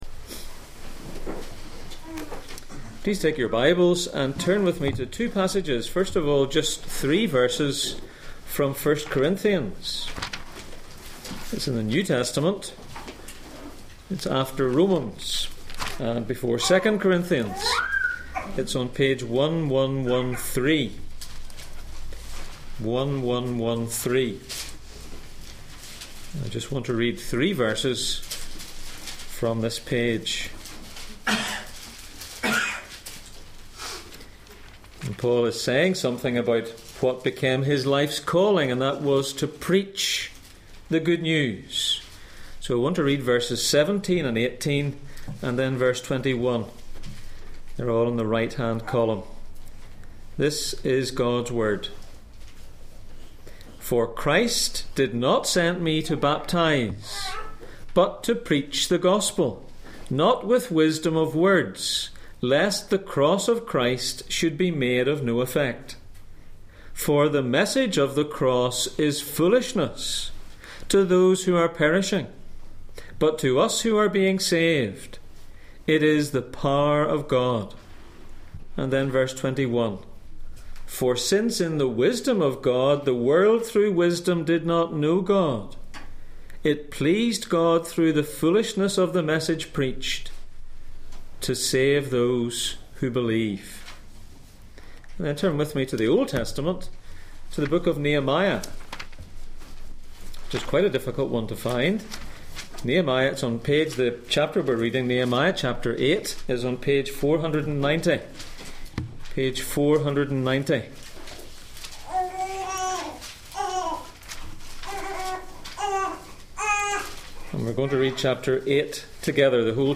Passage: Nehemiah 8:1-18 Service Type: Sunday Morning %todo_render% « The Book that Packs Real Power On which side of the gate are you?